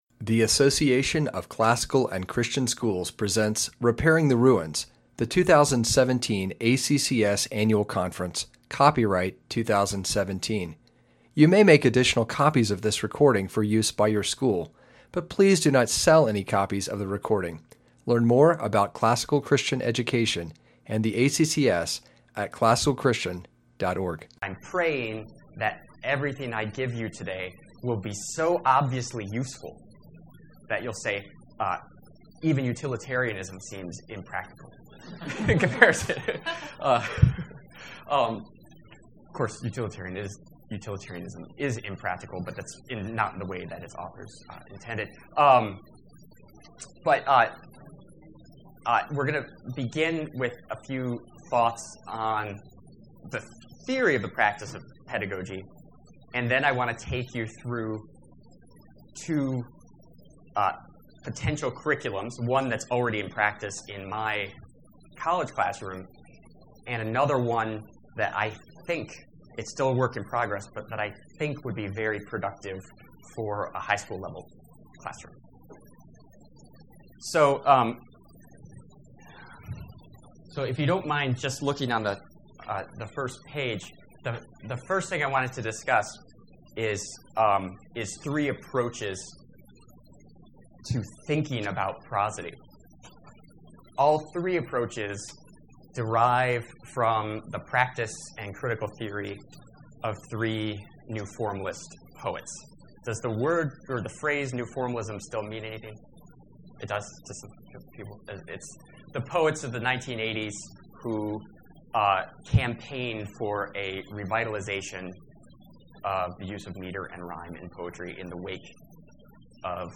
2017 Workshop Talk | 1:02:21 | All Grade Levels, Literature, Rhetoric & Composition